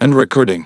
synthetic-wakewords
ovos-tts-plugin-deepponies_Ryotaro Dojima_en.wav